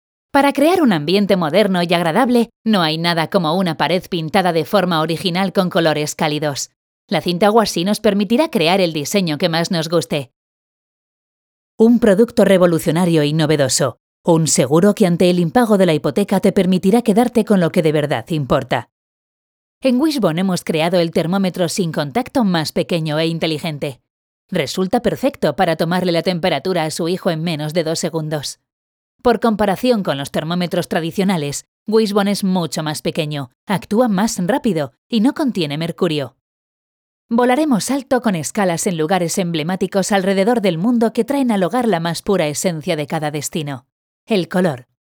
Locutora española 11 años experticia con home studio, disponibilidad, flexibilidad horarios y festivos y rapidez.
kastilisch
Sprechprobe: Industrie (Muttersprache):
Voice over freelance with home studio pro.